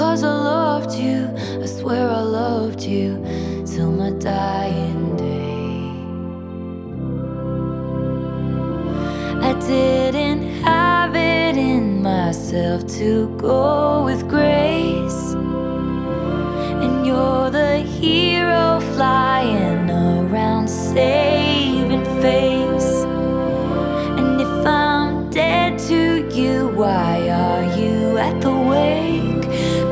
• Alternative
blends rock, goth, arena rock and gospel music